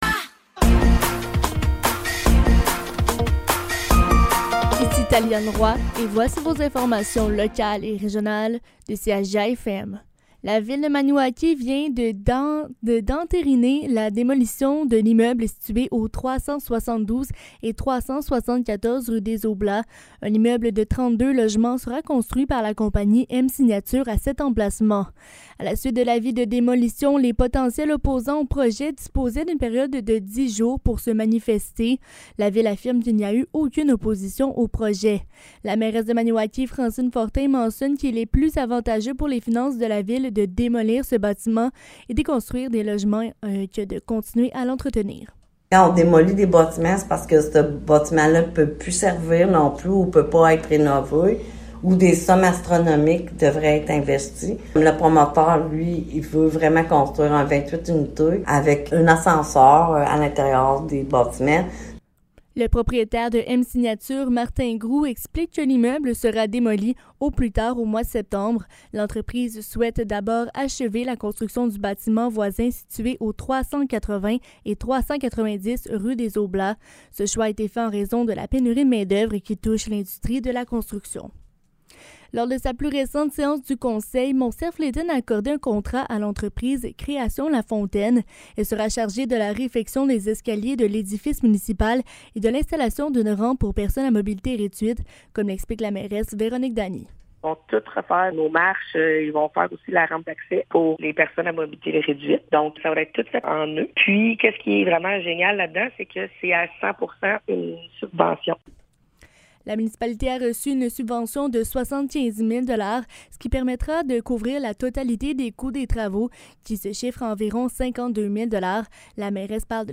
Nouvelles locales - 11 juillet 2023 - 15 h